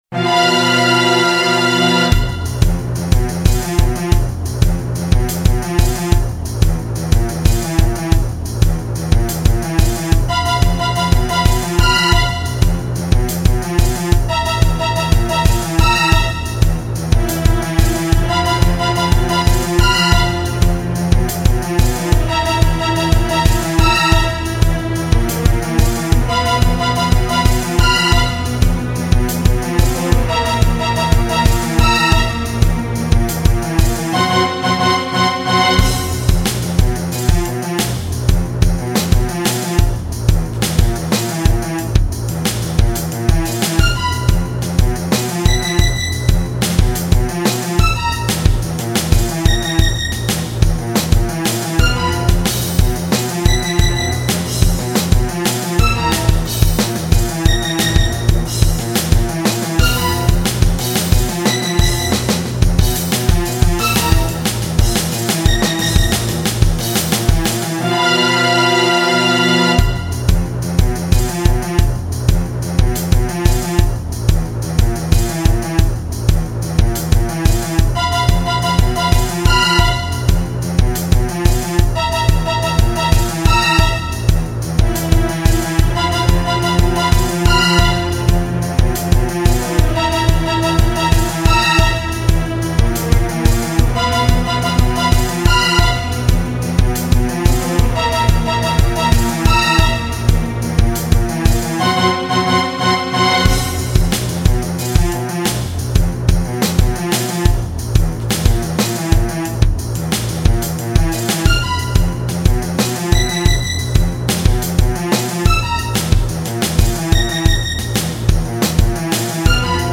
どちらもバイオリンなどの弦楽器がメインです。